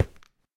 sounds / step / stone5.ogg
stone5.ogg